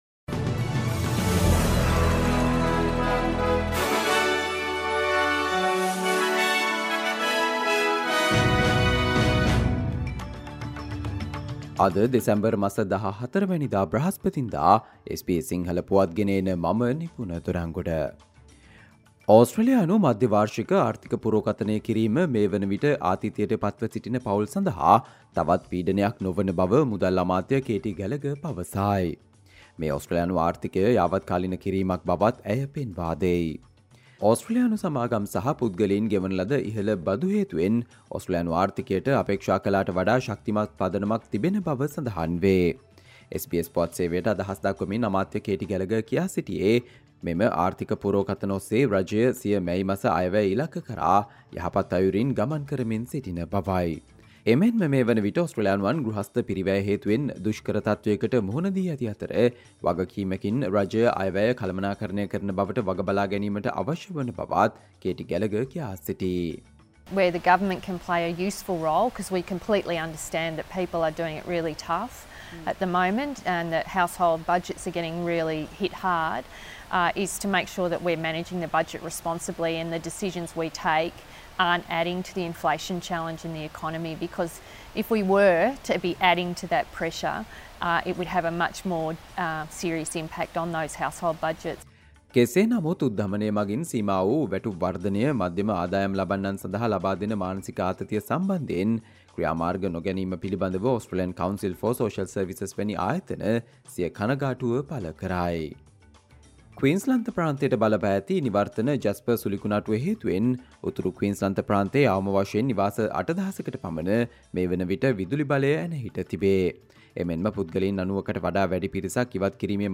Australia news in Sinhala, foreign and sports news in brief - listen, Thursday 14 December 2023 SBS Sinhala Radio News Flash